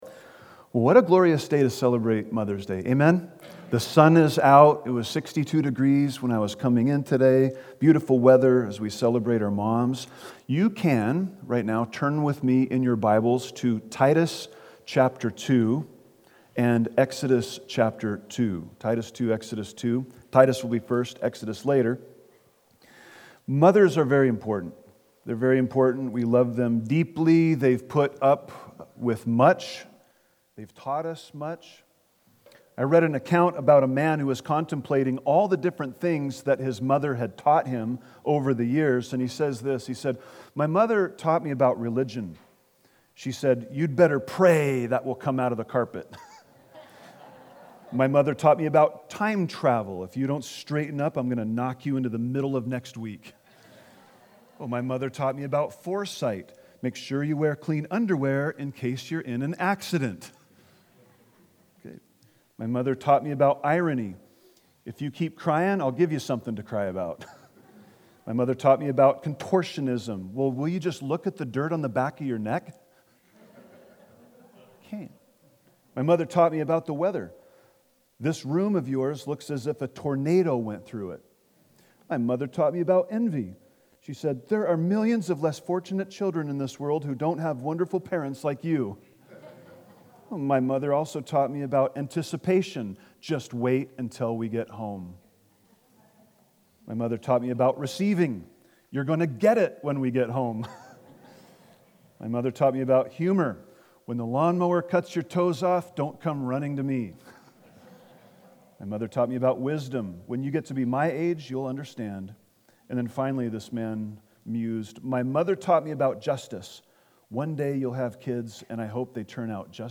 A message from the series "Topical."